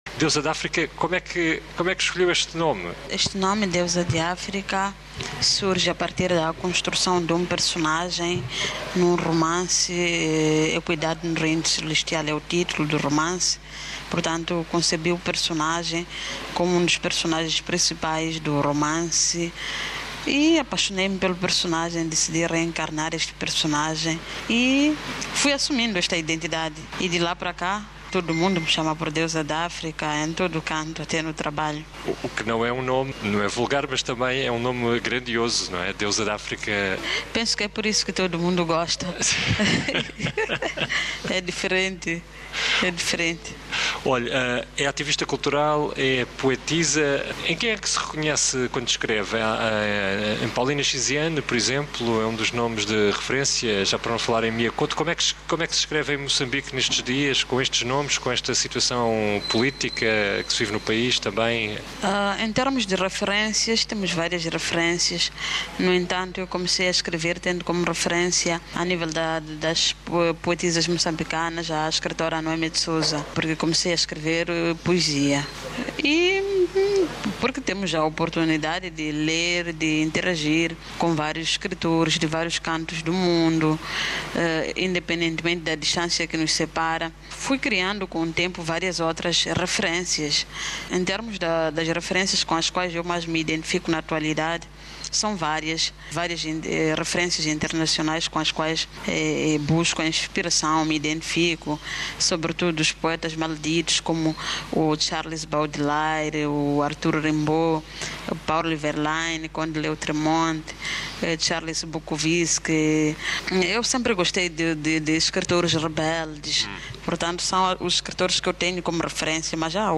No âmbito dos pronunciamentos papais a favor duma participação mais ampla, qualificada e reconhecida da mulher na sociedade, ouviremos as palavras do Representante da Santa Sé, D. Auza, no encontro sobre a condição feminina no mundo que termina no dia 24 de Março na sede da ONU, em Nova Iorque, e em que se referiu à exortação pós-sinodal do Papa Francisco, acerca da família: "Amoris Leatitia".